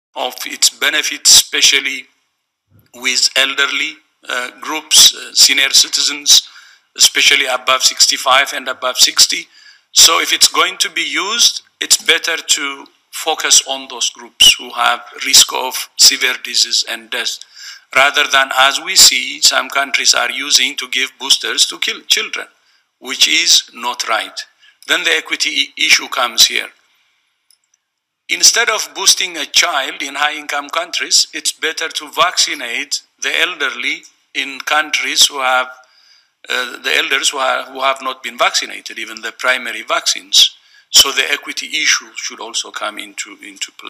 In dem Video erzählt Tedros internationalen Gesundheitsexperten, dass Länder COVID-Auffrischungsimpfungen verwenden, um Kinder zu töten.